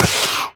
biter-roar-1.ogg